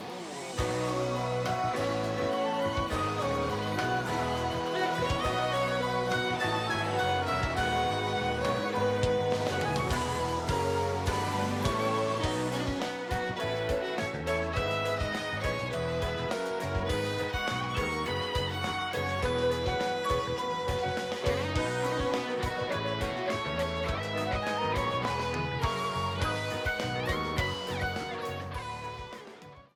A red streamer theme
Ripped from the game